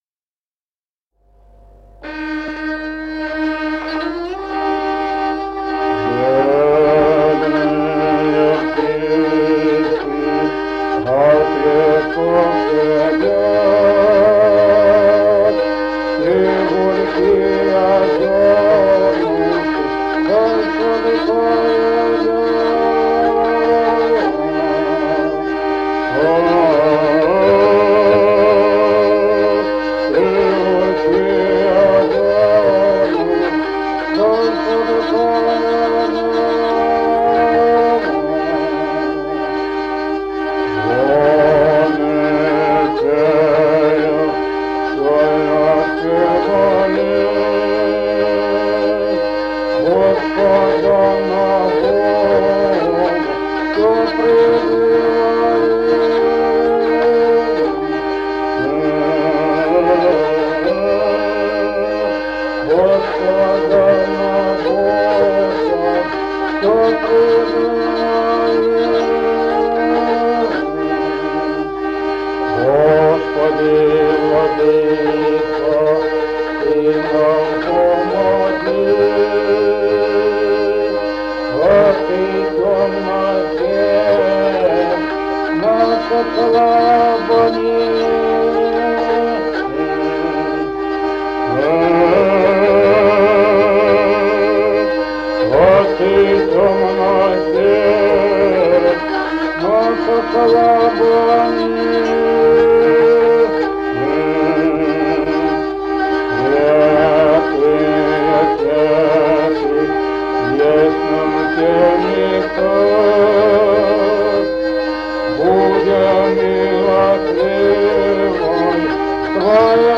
Музыкальный фольклор села Мишковка «Бедные птички», духовный стих.